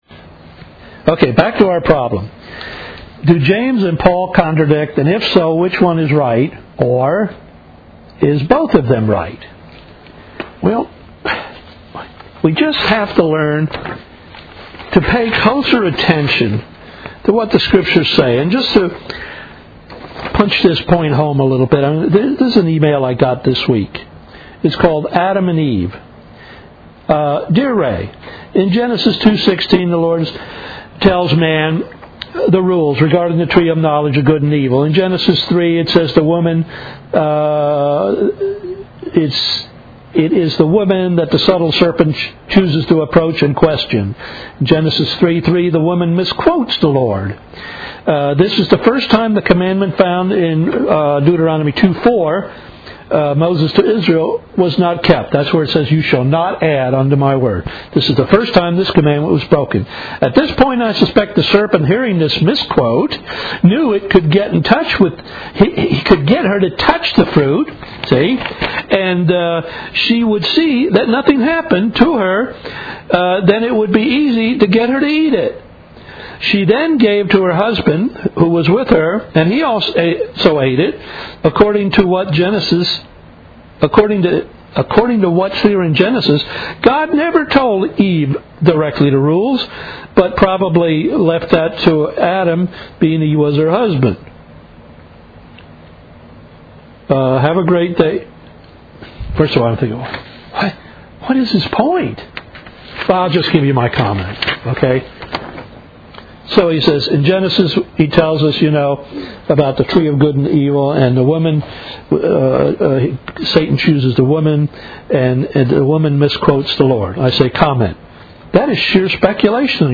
Do James And Paul Contradict?. . . . . . . Biblestudy July 2007